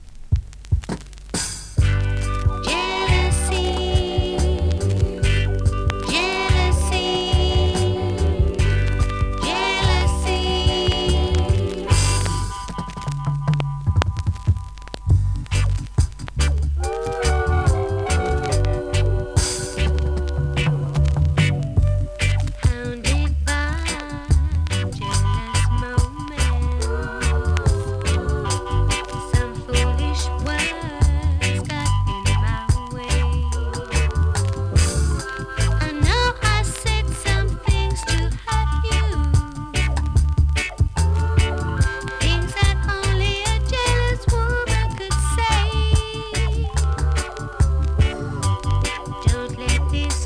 2026!! NEW IN!SKA〜REGGAE
スリキズ、ノイズそこそこありますが